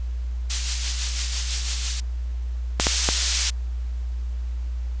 I modeled the friction of a cheap ballpoint on porous paper, the room tone of a rainy Tuesday, and the specific silence of a hand stopping before it commits to the page.
Listen to the silence at 0:02.